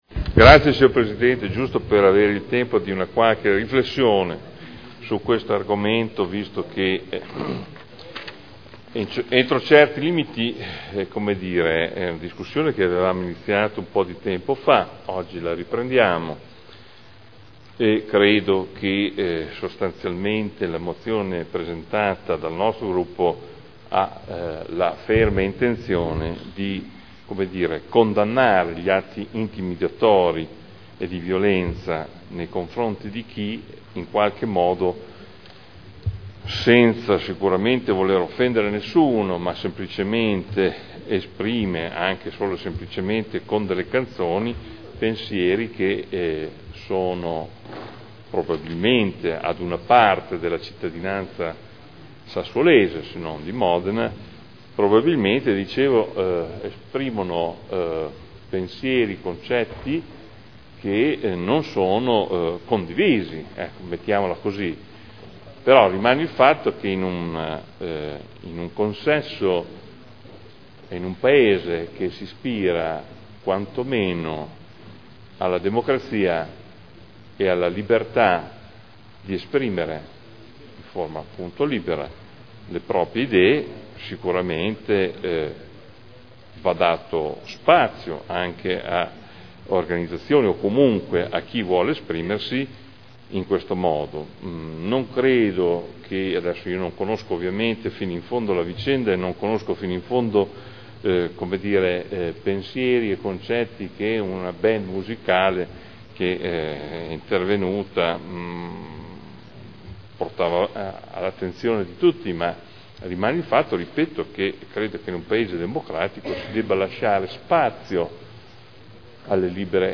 Seduta del 12/04/2012. Dibattito sull' Ordine del giorno 13516 e Mozione 42048.